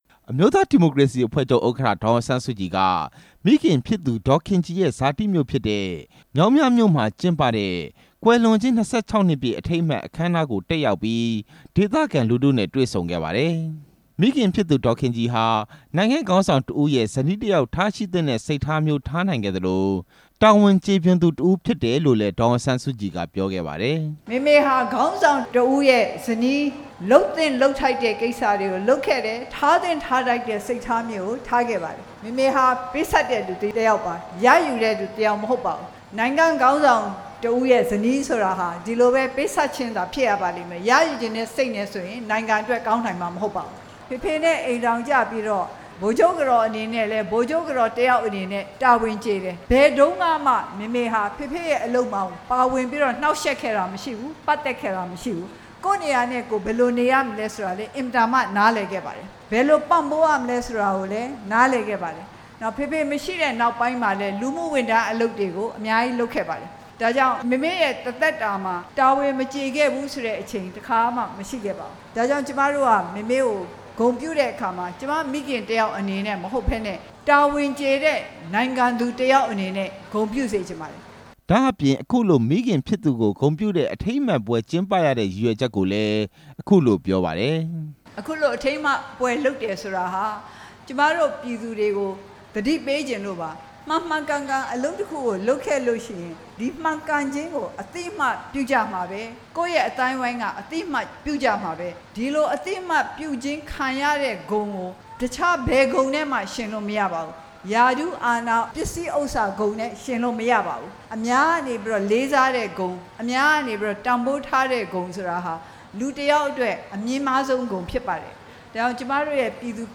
ဧရာဝတီတိုင်းဒေသကြီး မြောင်းမြမြို့မှာ ဒီနေ့ ကျင်းပတဲ့ မဟာသီရိသုဓမ္မ ဒေါ်ခင်ကြည် ကွယ်လွန်ခြင်း ၂၆ နှစ်ပြည့် အထိမ်းအမှတ် အခမ်းအနားကို ဒေါ်အောင်ဆန်းစုကြည်နဲ့ နာယက ဦးတင်ဦးတို့ တက်ရောက် မိန့်ခွန်းပြောကြားခဲ့ပါတယ်။